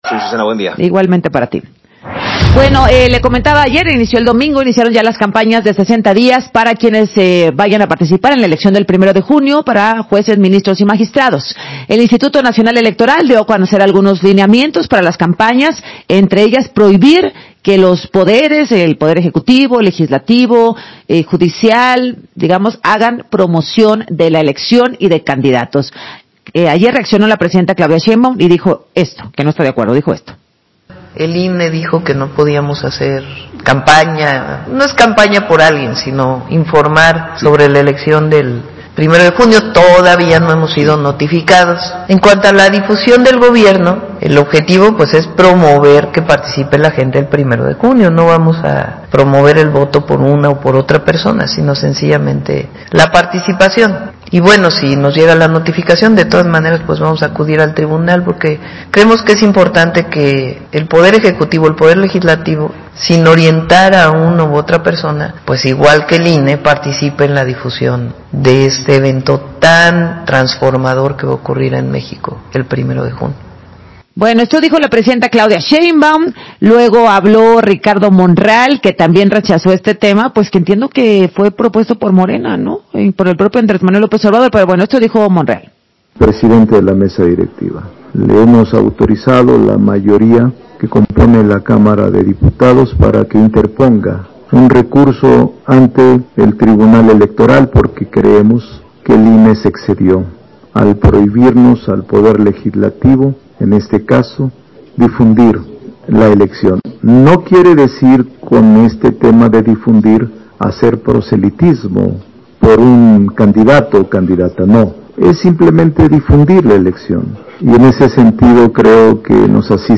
Entrevista de la Consejera Electoral Claudia Zavala con Azucena Uresti para Radio Fórmula